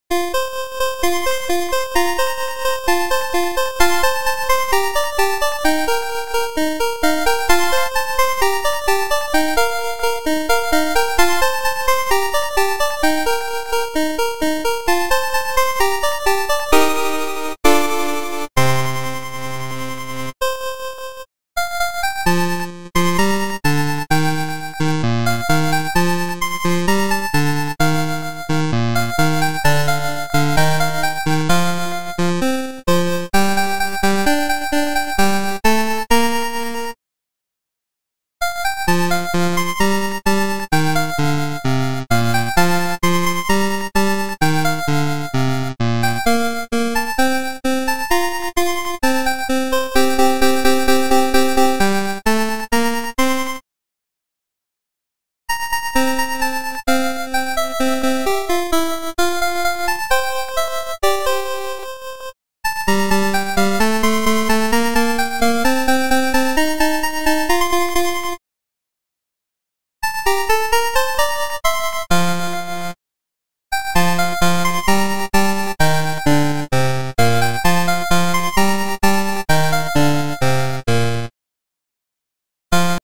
genre:chiptune
genre:remix